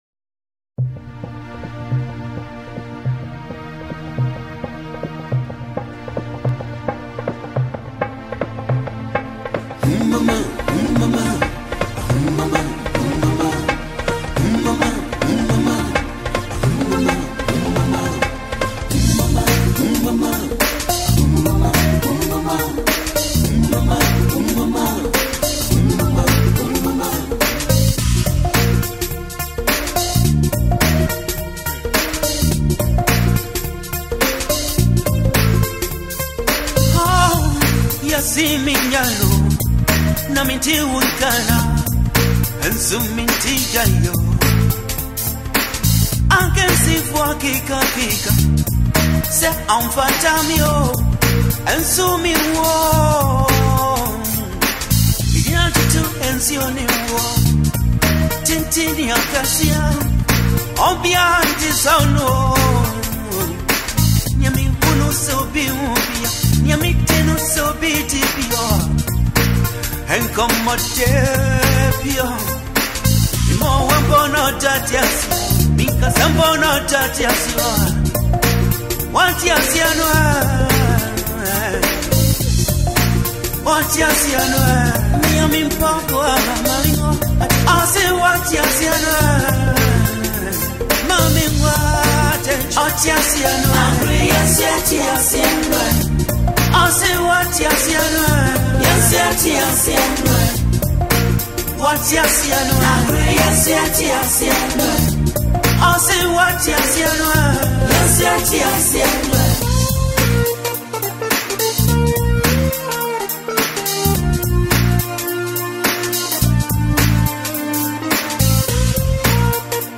soulful, wise, and emotional.